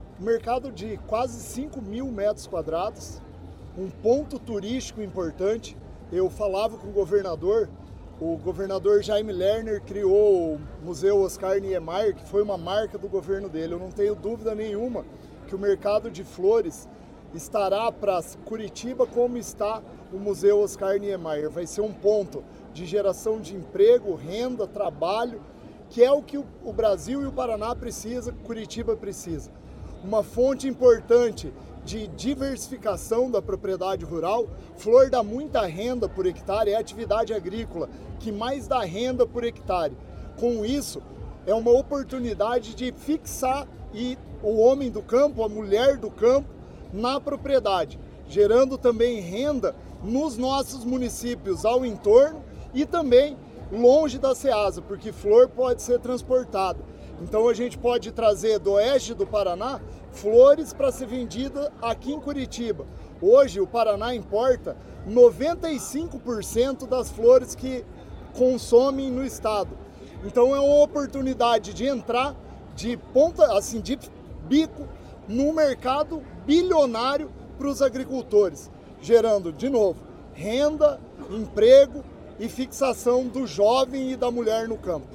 Sonora do diretor-presidente da Ceasa Paraná, Éder Bublitz, sobre o anúncio do novo Mercado de Flores da Ceasa